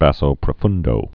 (băsō prə-fŭndō, bäsō prə-fndō)